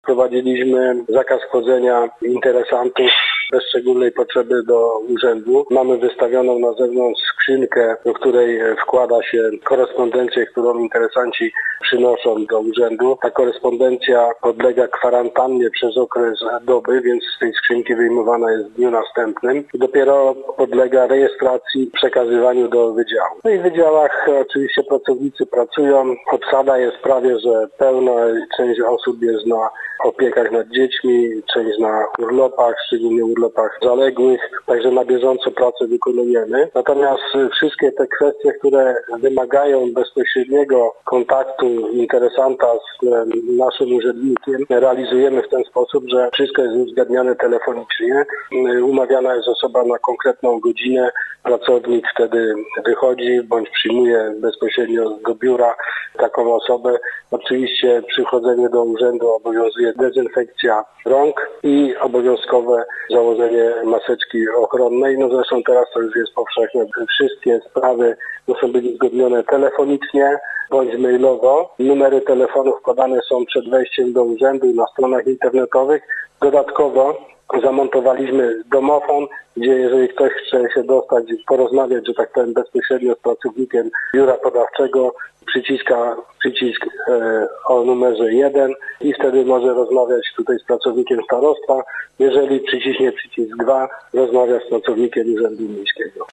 – mówił starosta, Andrzej Szymanek.